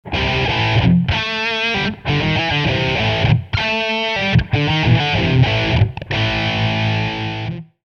DISTORSION
La distorsion : le signal est plus amplifié par un gain plus important. On l'appelle overdrive lorsque le son n'est ni trop saturé, ni trop crunch, idéal pour les rythmiques plus hard.
overdrive.mp3